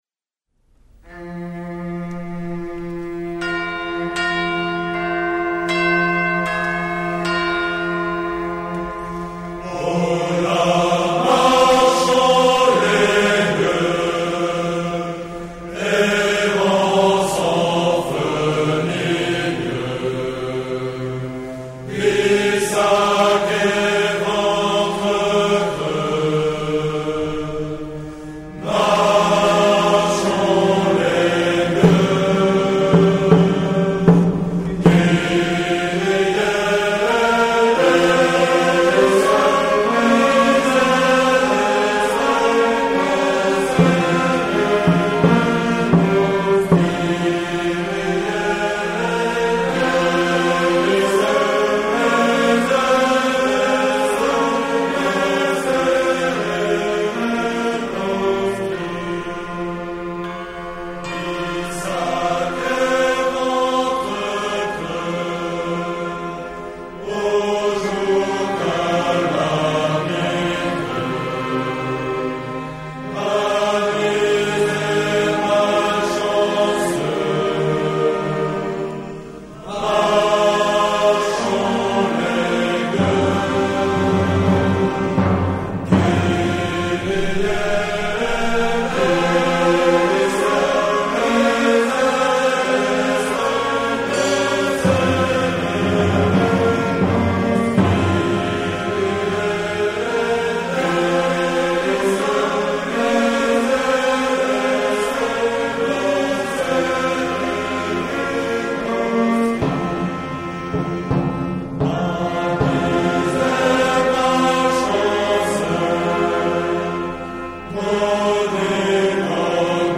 Chants scouts